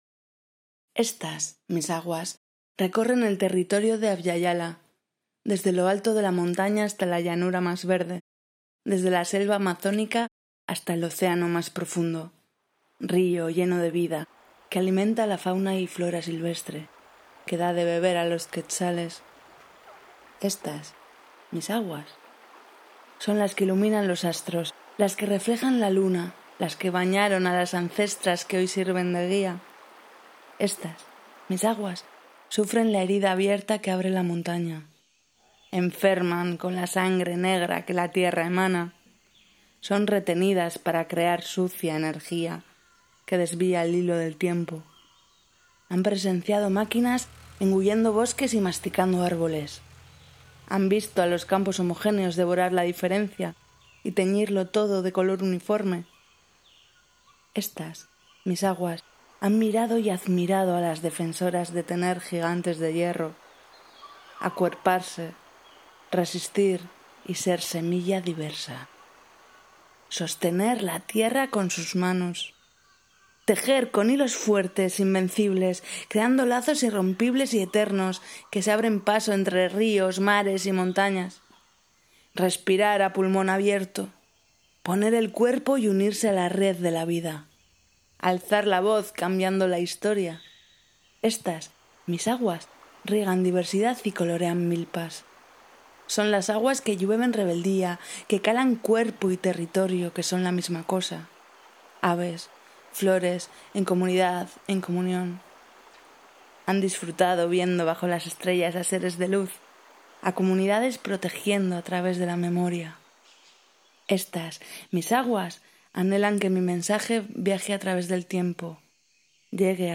Audiocuento-PROTECTORAS-Castellano.wav